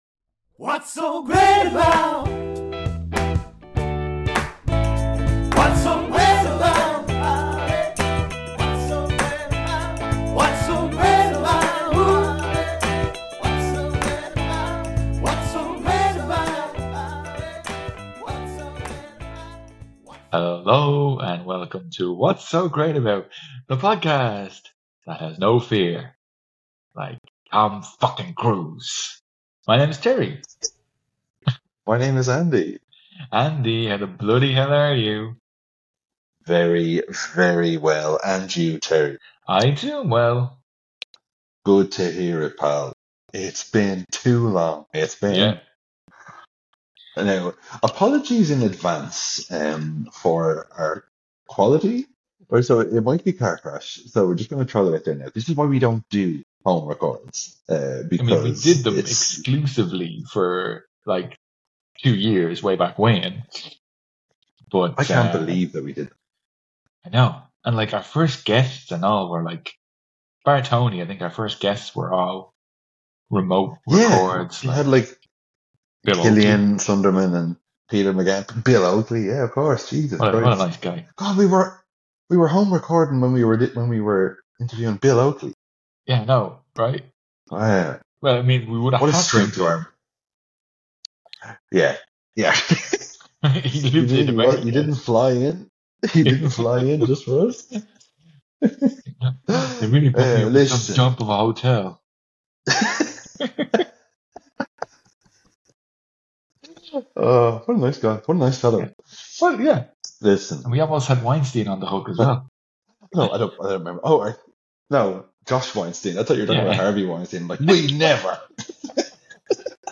Its a good ol fashioned HOME RECORD!
It means that while the sound quality is..... OK (we didnt even have mics), but the CHAT QUALITY?!!!
This is just a one off until we are properly back, we hope you dont mind the low fidelity Share Facebook X Subscribe Next All you Need is Death Top episodes The 80s by What's So Great About... 2000s by What's So Great About...